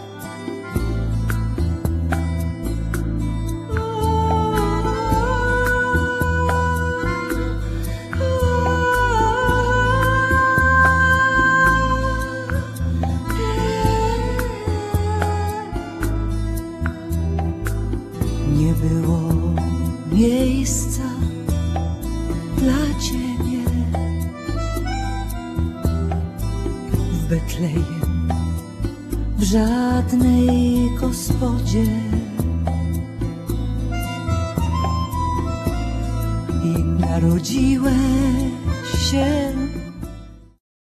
śpiew, wokalizy, recytacja